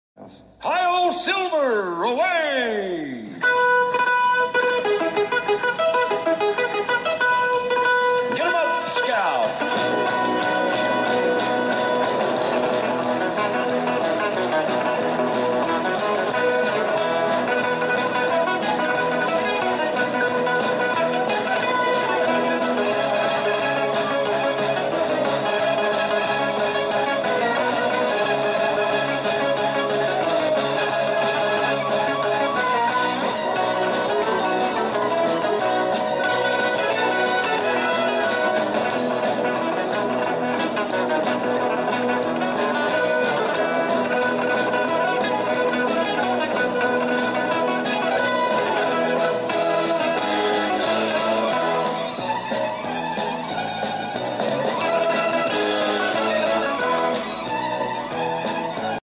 12-string guitar
incredible flatpicking speed and dexterity